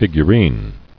[fig·u·rine]